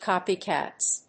/ˈkɑpiˌkæts(米国英語), ˈkɑ:pi:ˌkæts(英国英語)/